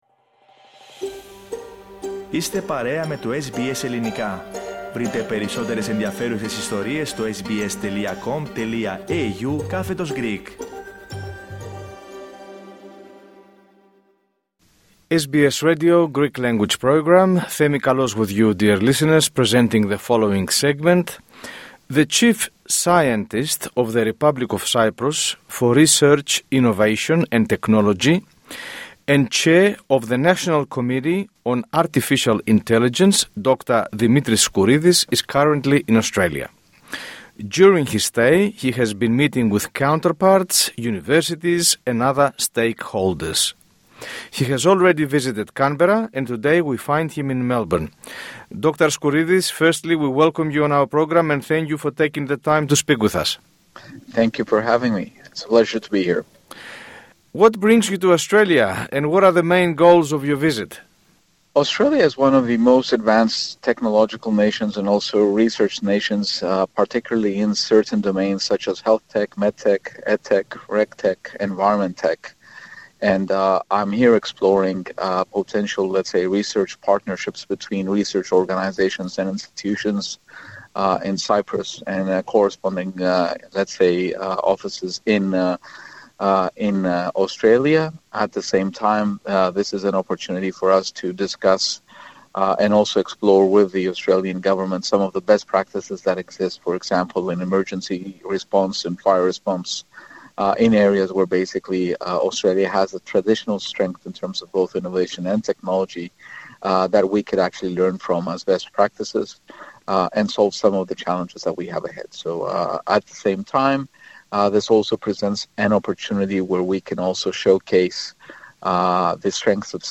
Speaking to the Greek Program of SBS Radio, Dr. Skouridis explained the role of the Chief Scientist in Cyprus, his responsibilities, and the importance the country places on the development and regulation of Artificial Intelligence. He also referred to the sectors where Cyprus has a comparative advantage, the challenges faced by the research community, and the potential for substantial collaboration with Australia.